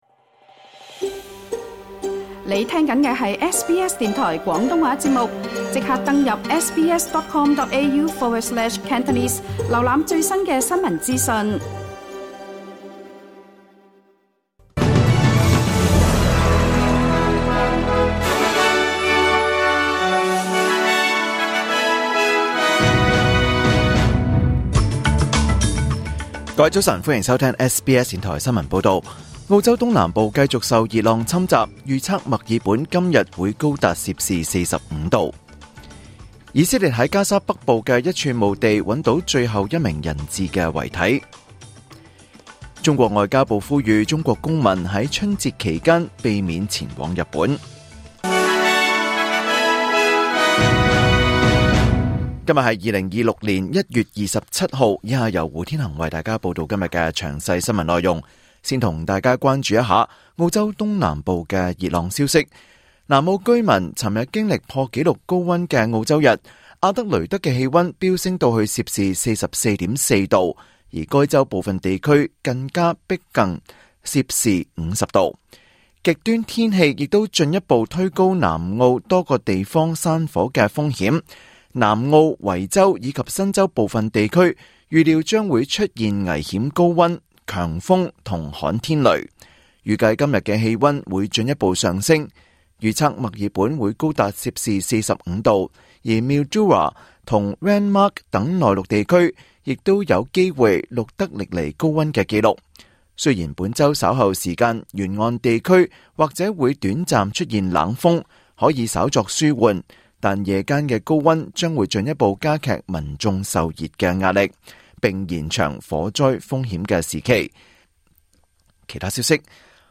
2026年1月27日SBS廣東話節目九點半新聞報道。